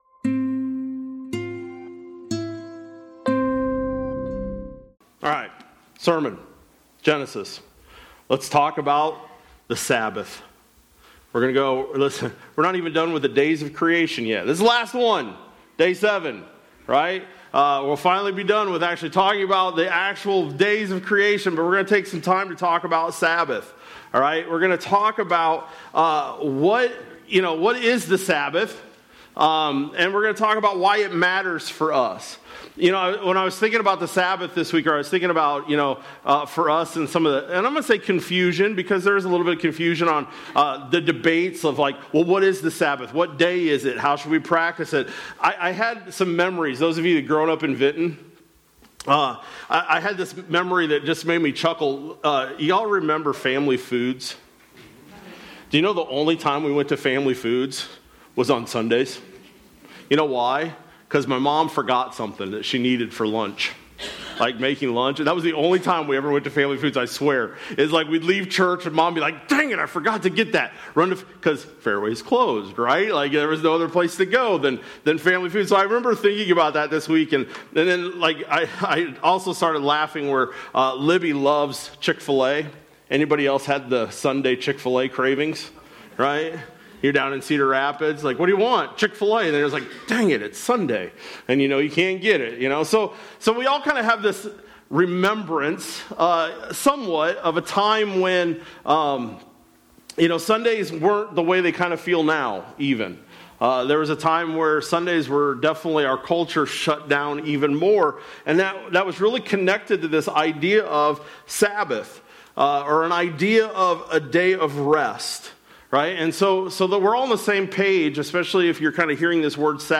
Feb-15-26-Sermon-Audio.mp3